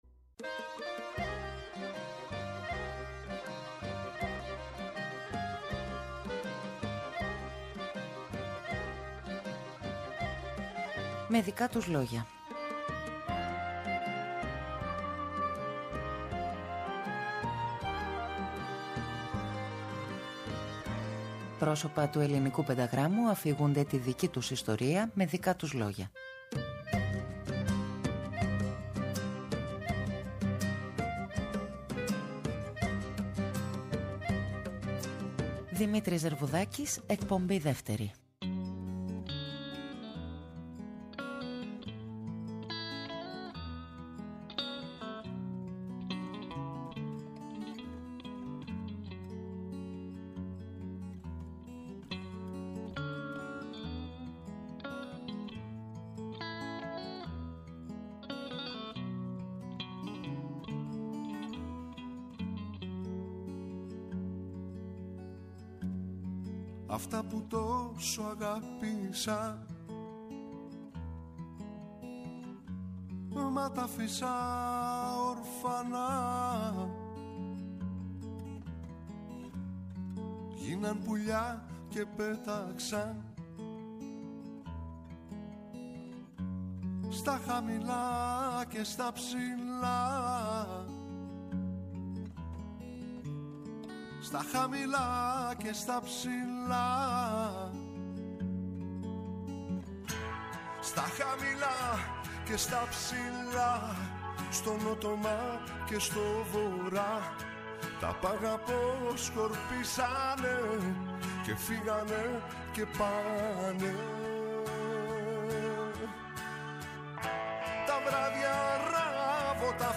Θεσσαλονίκη, η πόλη και ο πολιτισμός της, η μουσική «σχολή» της Θεσσαλονίκης, η πολιτική σκέψη στην καθημερινότητα, η ουσία της τέχνης, το «Κύμα», «Τα Παράξενα Πουλιά», ο τραγουδοποιός και ο ερμηνευτής. Για 3 ώρες, 3 εκπομπές, 3 Κυριακές ο Δημήτρης Ζερβουδάκης μοιράζεται απόψεις, σκέψεις και στιγμιότυπα και αφηγείται τη δική του ιστορία..